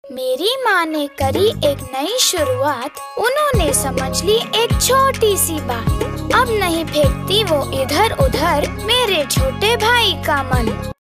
Radio spot Hindi TSC child excreta disposal pit children